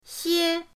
xie1.mp3